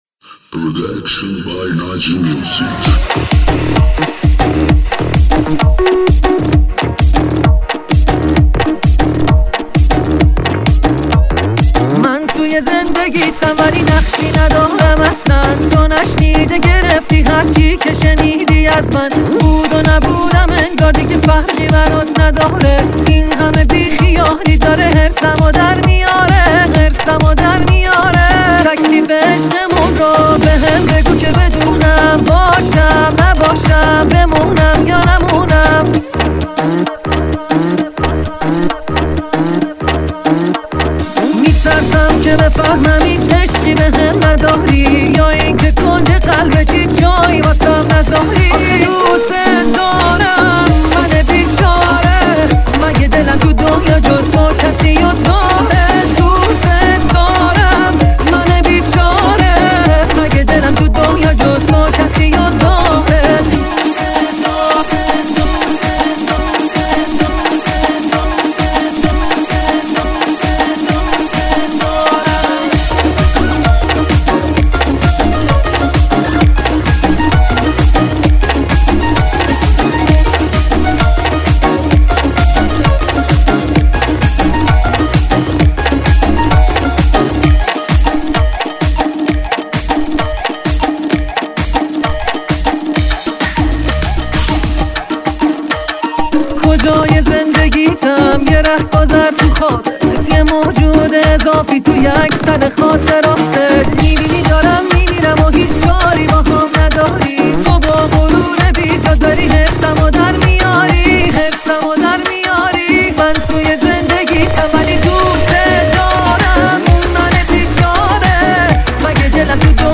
بهترین ریمیکس برای رقص دونفره
ریمیکس شاد قری رقصی